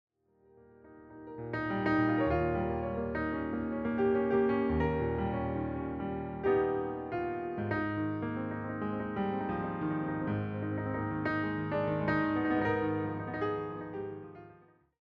all reimagined through solo piano.